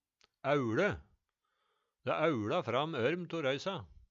Høyr på uttala Ordklasse: Verb Attende til søk